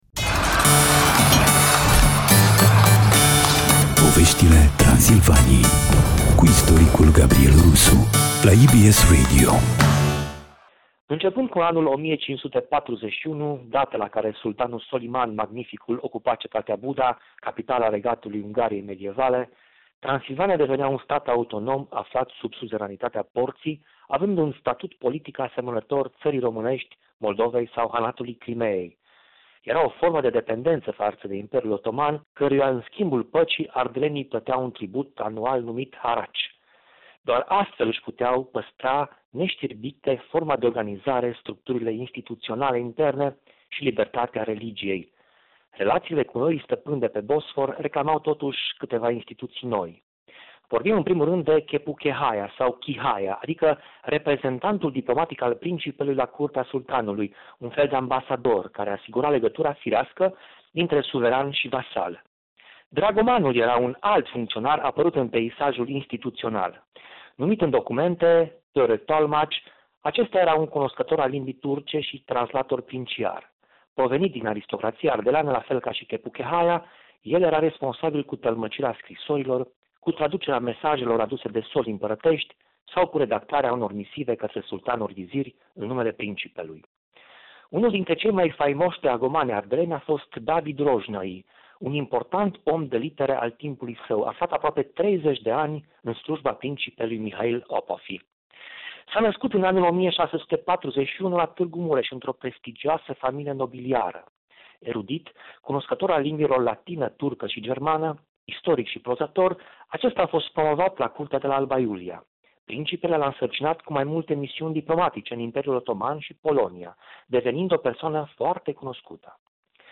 Istoricul